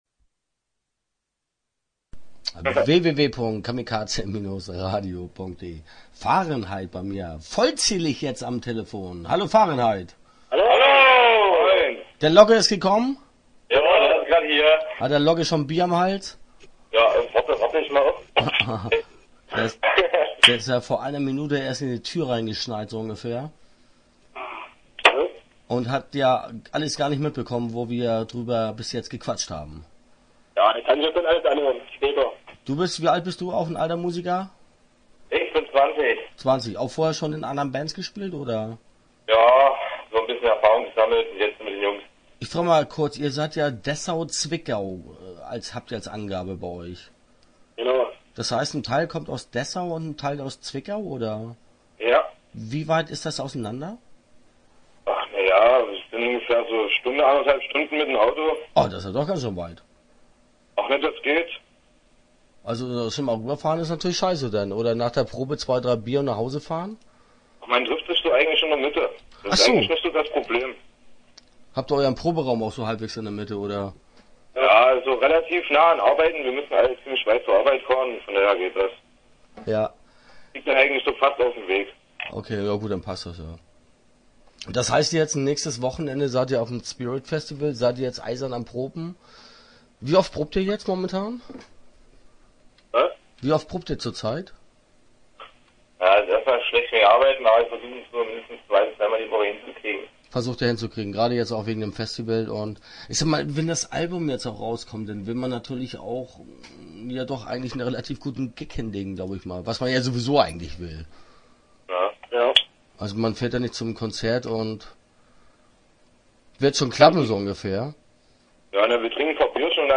Start » Interviews » Fahrenheit